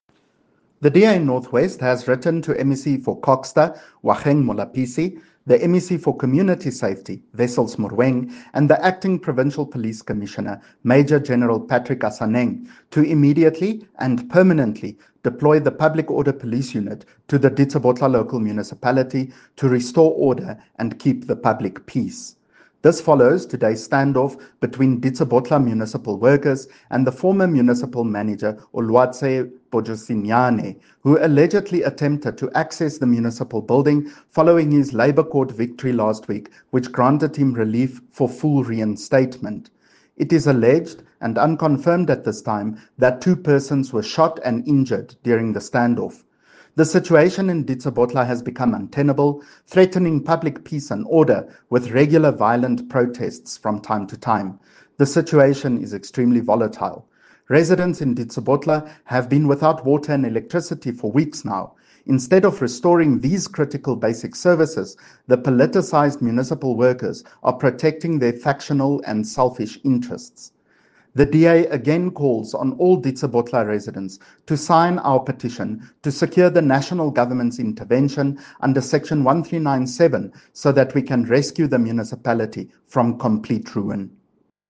Note to Broadcasters: Please find linked soundbites in
Afrikaans by CJ Steyl MPL.